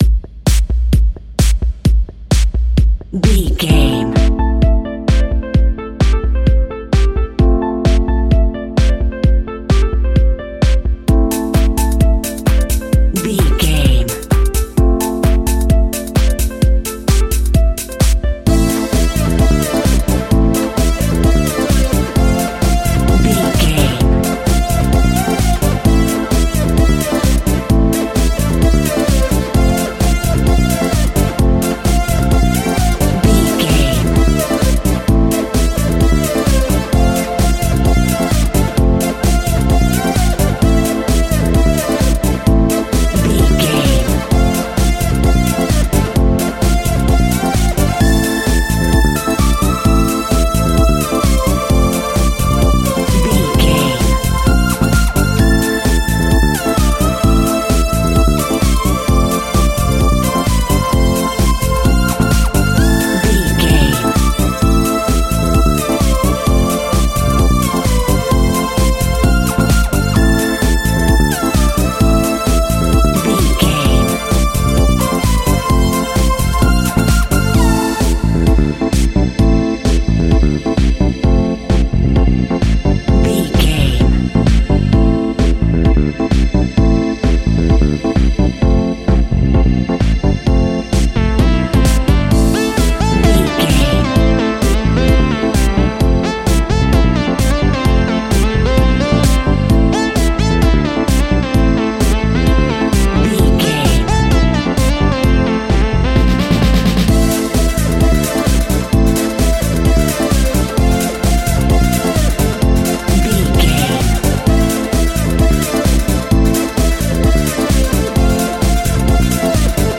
Aeolian/Minor
groovy
uplifting
energetic
strings
bass guitar
synthesiser
drums
drum machine
disco
nu disco
upbeat
instrumentals
funky guitar
clavinet
horns